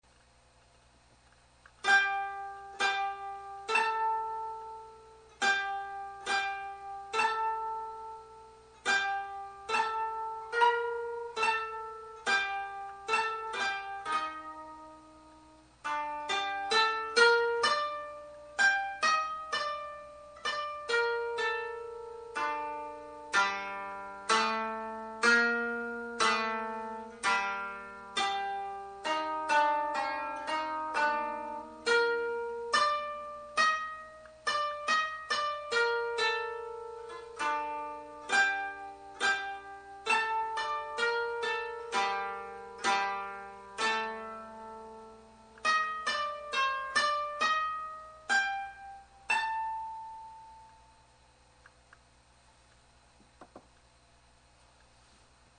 The Koto